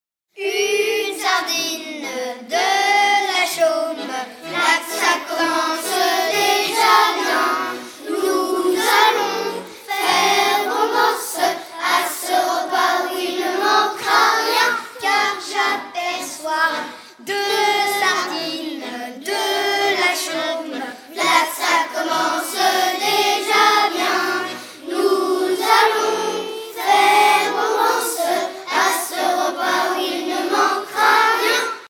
ronde à trois pas
Genre énumérative
Les enfants des Olonnes chantent